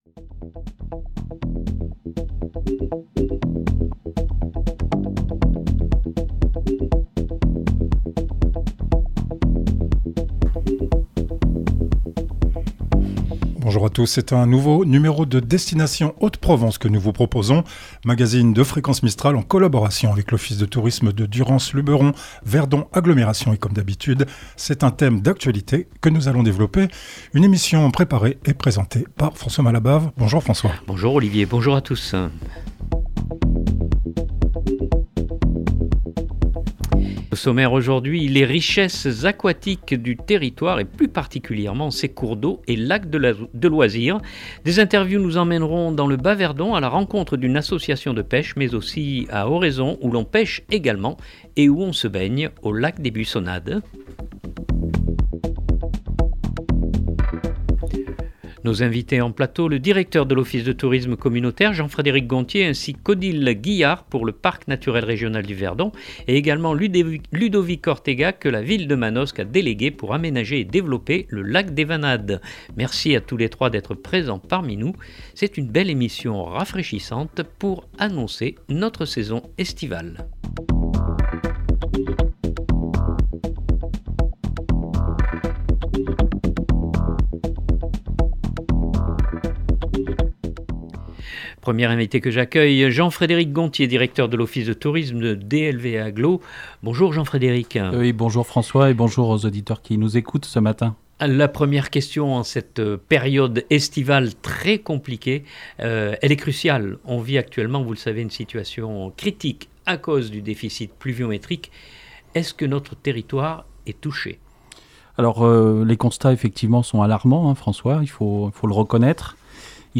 Au sommaire aujourd’hui, les richesses aquatiques du territoire et plus particulièrement ses cours d’eau et lacs de loisirs. Des interviews nous emmèneront dans le Bas Verdon à la rencontre d’une association de pêche mais aussi à Oraison où l’on pêche également et on se baigne au lac des Buissonnades.